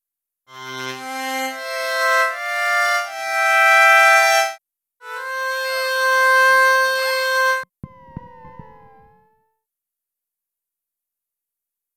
Track 16 - Synth 03.wav